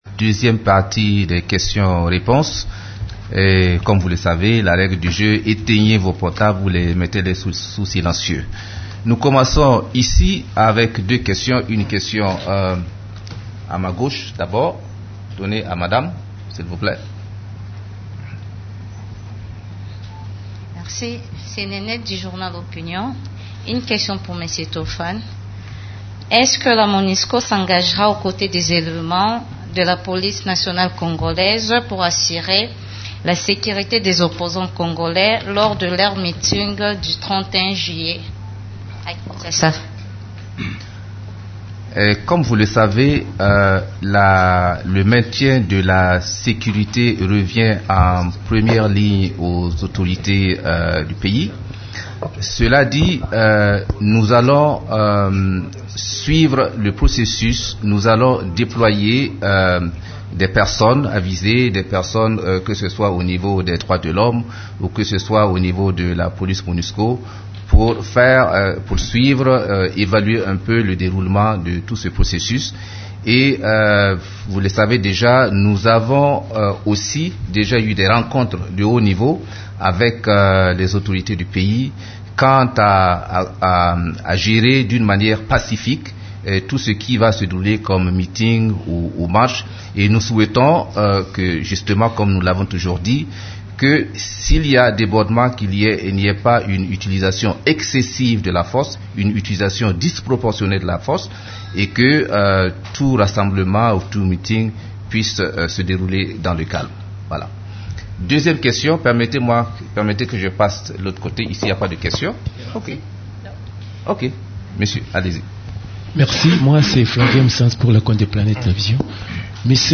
Conférence de presse du 27 juillet 2016
La conférence de presse hebdomadaire des Nations unies du mercredi 27 juillet à Kinshasa a porté sur les activités des composantes de la MONUSCO, les activités de l’équipe-pays et la situation militaire.
Vous pouvez écouter la première partie de la conférence de presse: